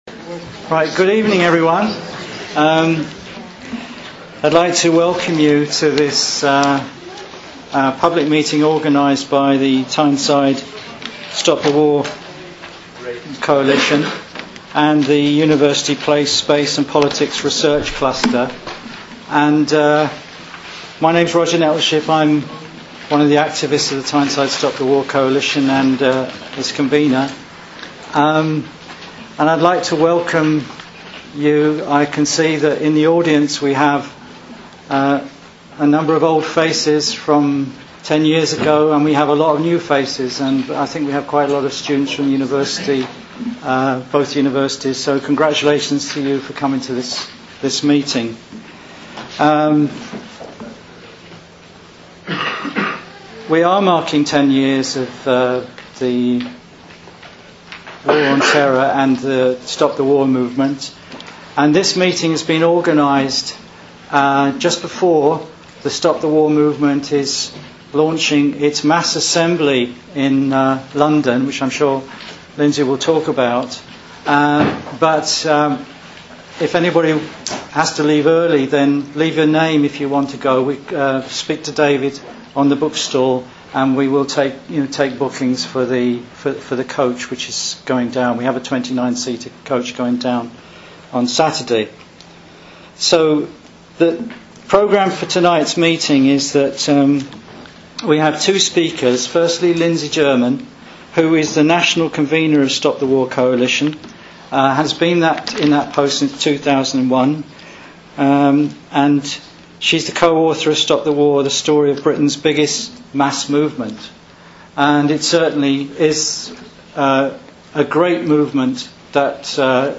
On Wednesday October 5th Tyneside Stop the War held a successful public Meeting in the University of Newcastle -upon Tyne.